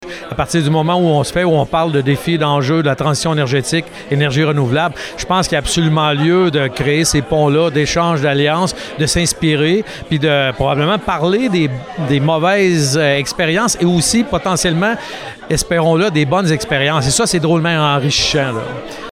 Plusieurs étaient présents lors d’une conférence de presse de lundi au vignoble le Clos de l’île à Saint-Angèle-de-Laval.